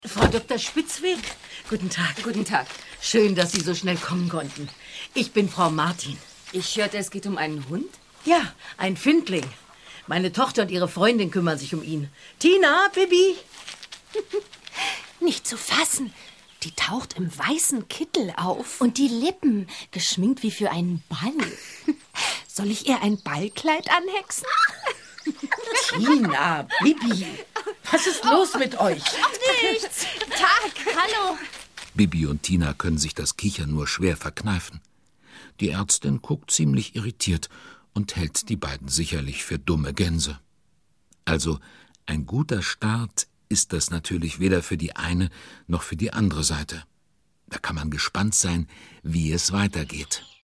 Ravensburger Bibi und Tina - Die Tierärztin ✔ tiptoi® Hörbuch ab 6 Jahren ✔ Jetzt online herunterladen!
Hoerprobe-Bibi_und_Tina-Die_Tieraerztin.mp3